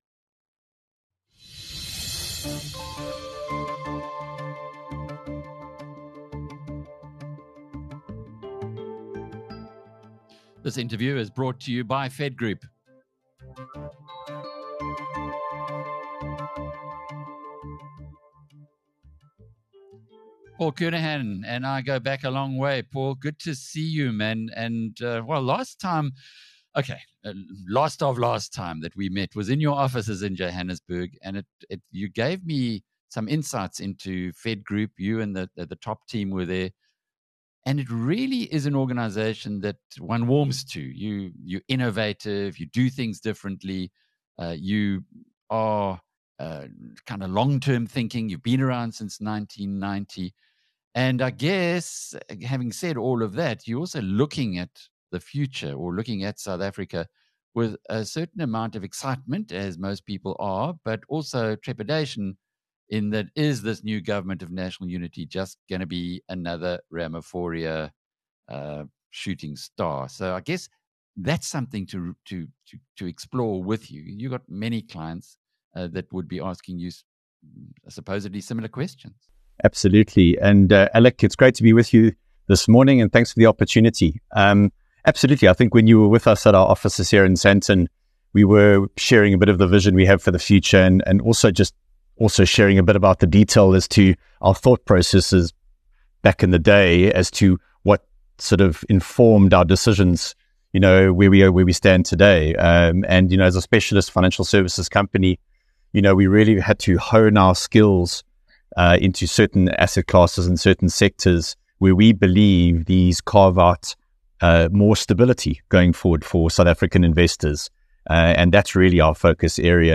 The conversation underscored the necessity of telling a compelling narrative about South Africa's recovery to build momentum and encourage both local and international investment.